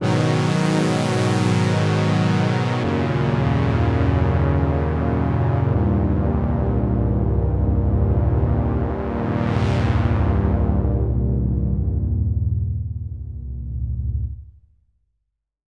So as a point of comparison I fired up the Prophet to play with the Pan Spread, I think this helps demonstrate the kind of width that’s possible with a poly synth that you can’t easily fix in post with a lot of these tricks.
This has no effects on it, just placing the different voices in the stereo field.